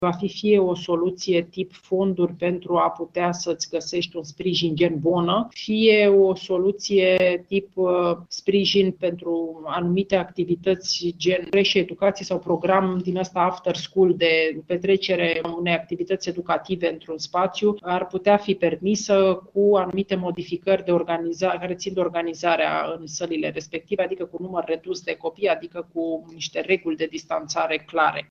Este anunțul făcut de Ministrul Muncii, Violeta Alexandru, în cadrul unei discuţii cu antreprenorii organizată de Clubul Oamenilor de Afaceri Liberali.